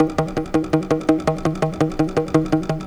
Flute 51-01.wav